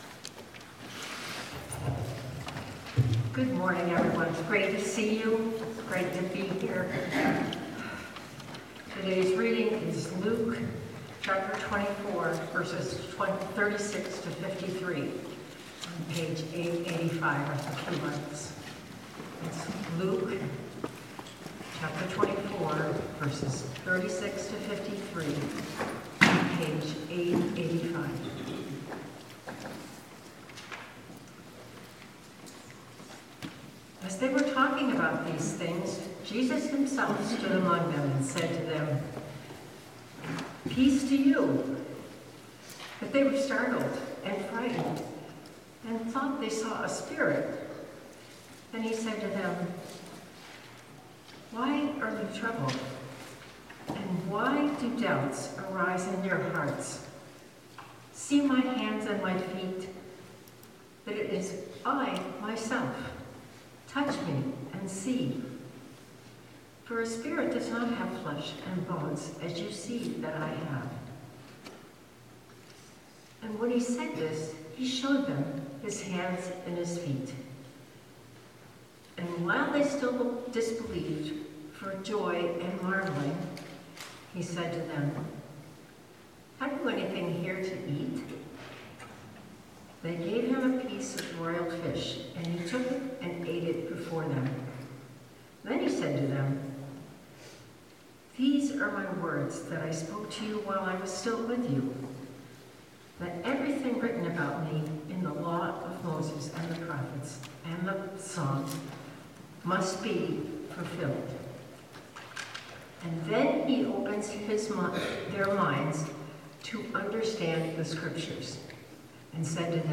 Passage: Luke 24:36:53 Sermon